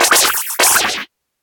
Cri d'Embrochet dans Pokémon HOME.